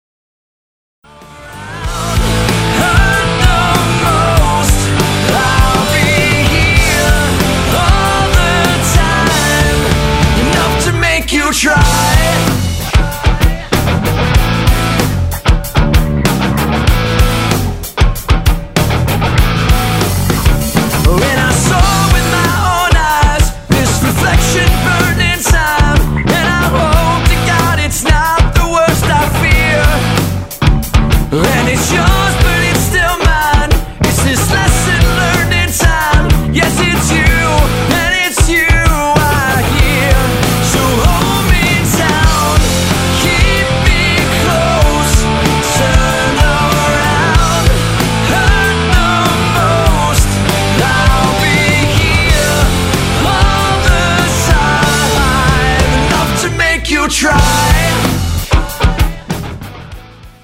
Drums
Vocals/Guitar
Bass
Guitar
straight-ahead rock & roll songwriting